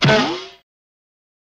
На этой странице собраны веселые и яркие звуки мультяшных драк: звонкие удары, шлепки, взвизгивания и другие забавные эффекты.
Звуковое сопровождение удара по мультяшному герою